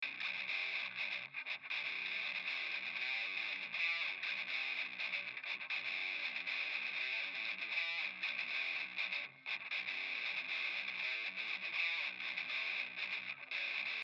Frequenze stazionarie
per farvi sentire in modo preciso di cosa parlo, isolandole dal resto del suono della chitarra.
Le risonanti vere, quelle da eliminare, non modulano, ossia non cambiano a seconda dell'accordo o di cosa fa la chitarra: sono sempre presenti, come un fischio perenne.